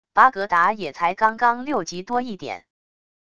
巴格达也才刚刚六级多一点wav音频生成系统WAV Audio Player